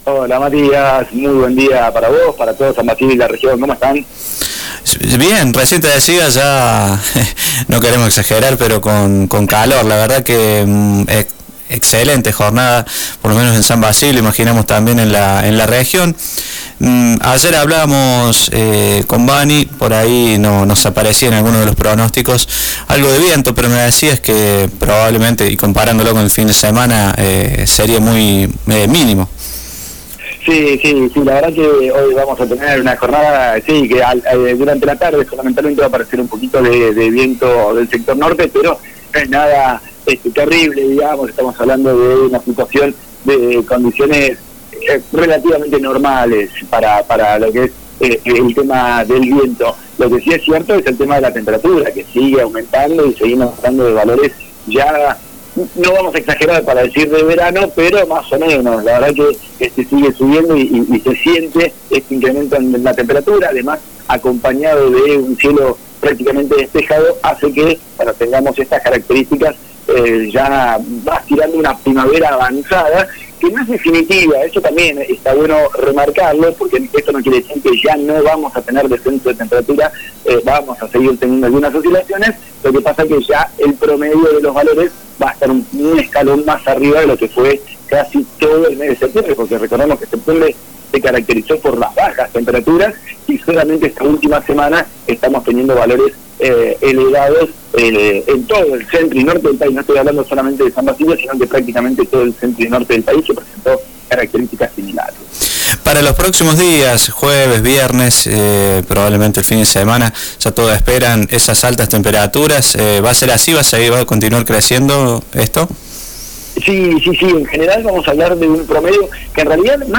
PRONOSTICO-Miercoles-25.mp3